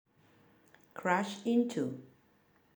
KFhlMzlU20M_crash-into.mp3